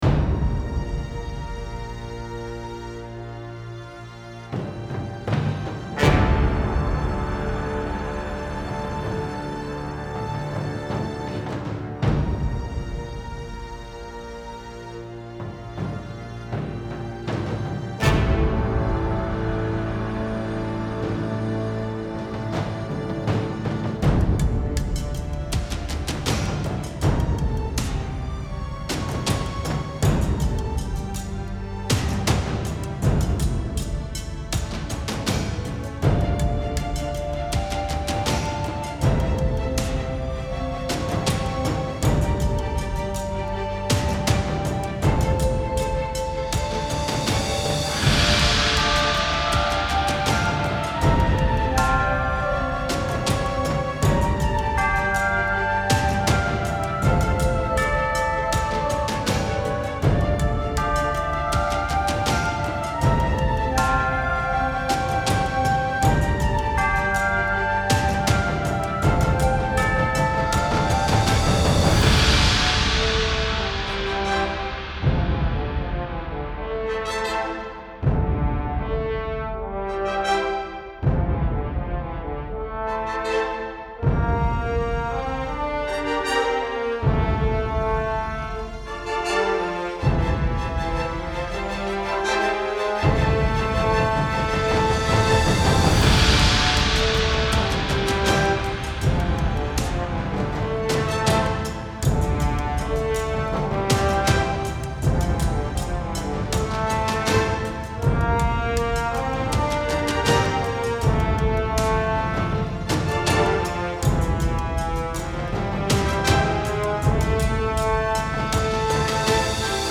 Style Style Orchestral, Soundtrack
Mood Mood Epic, Uplifting
Featured Featured Bells, Brass, Choir +4 more
BPM BPM 80